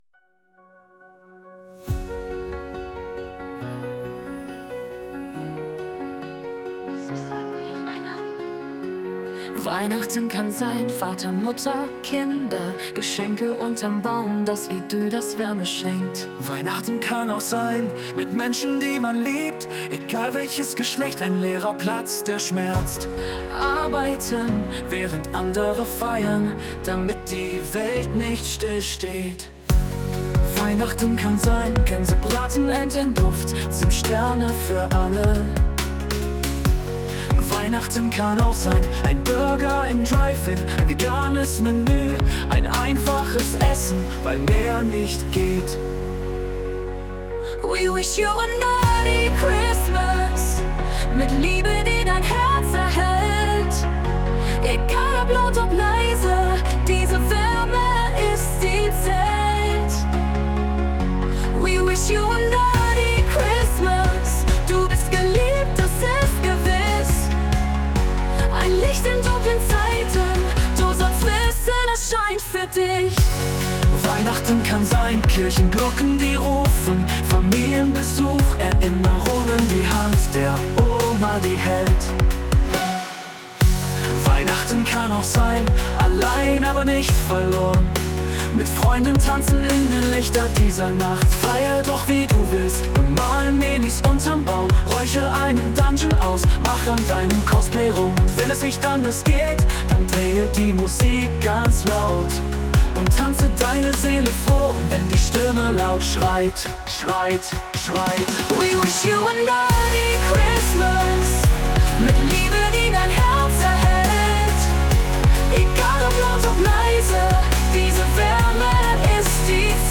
Auf dem Blog der nerdchurch habe ich dieses wirklich tolle Weihnachtslied in drei Versionen gefunden.
Nerdy-Christmas-Duett.mp3